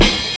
Snare (OKAGA, CA).wav